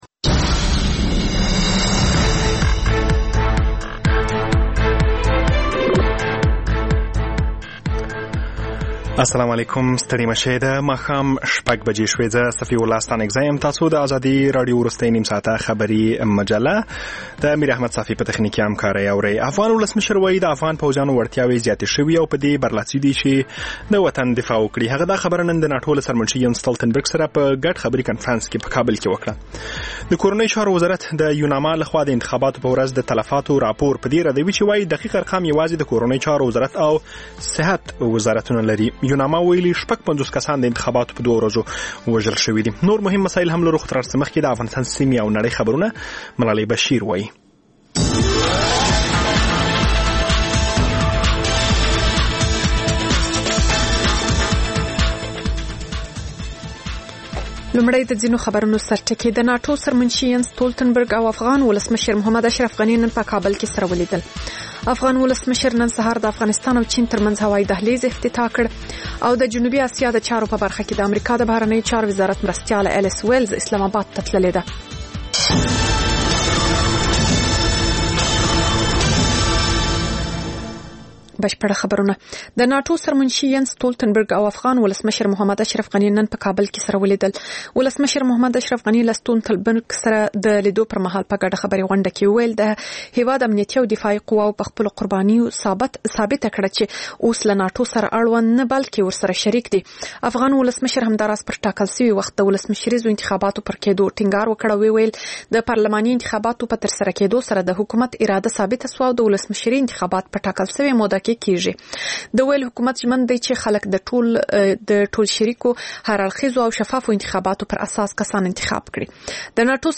ماښامنۍ خبري مجله